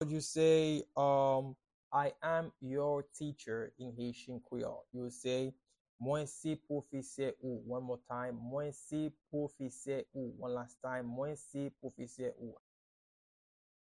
Pronunciation and Transcript:
I-am-your-teacher-in-Haitian-Creole-Mwen-se-pwofese-ou-pronunciation-by-a-Haitian-teacher-1.mp3